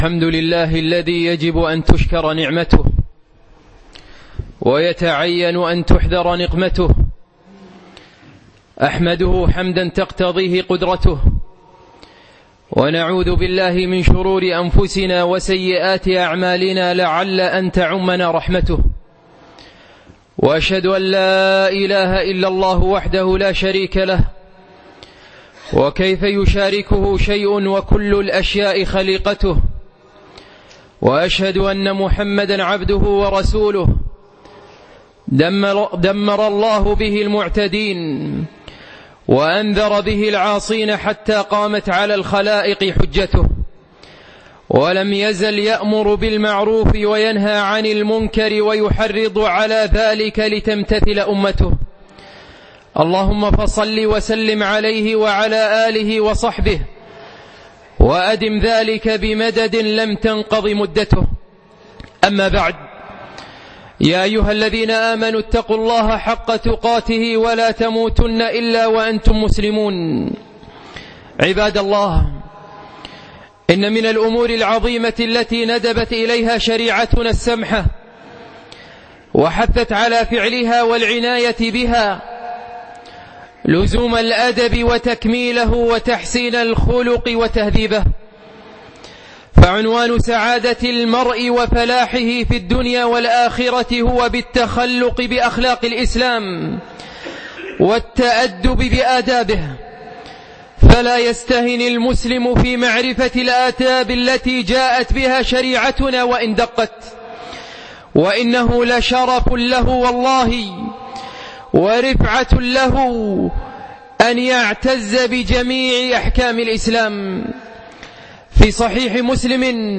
خطبة بتاريخ 6 3 2015